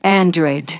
ovos-tts-plugin-deepponies_Celestia_en.wav